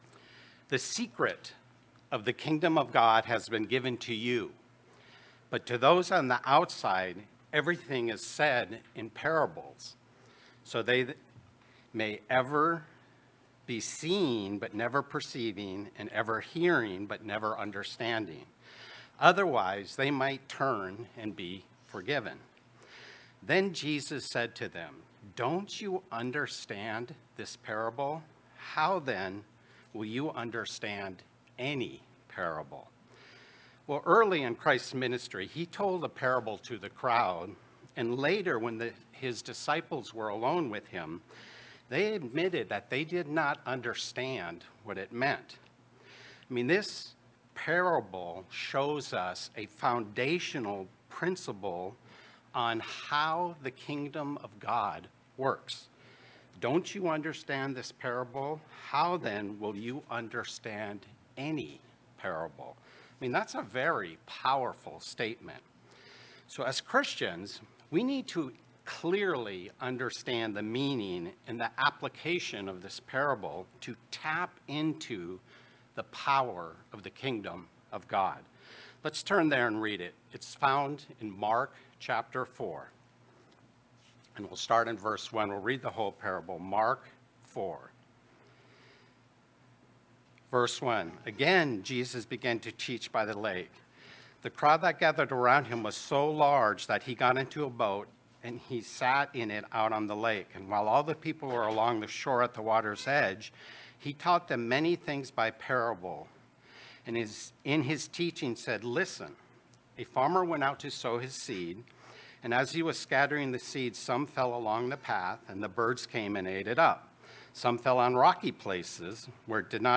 Sermons
Given in Worcester, MA